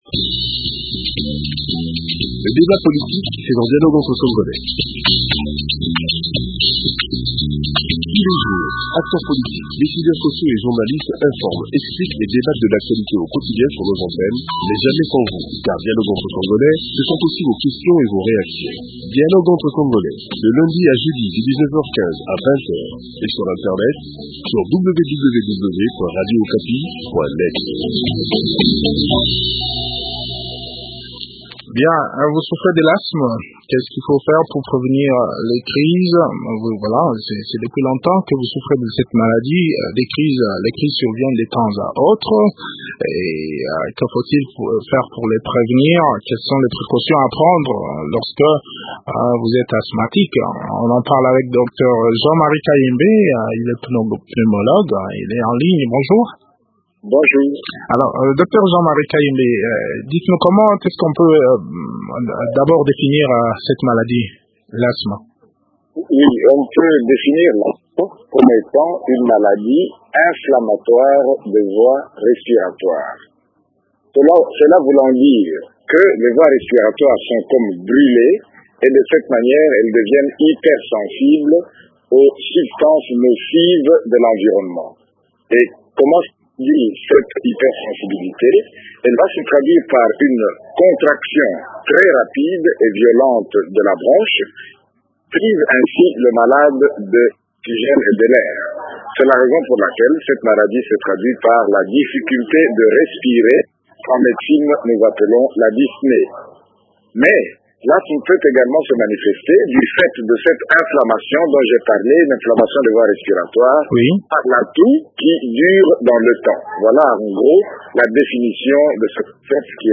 Le point du sujet dans cet entretien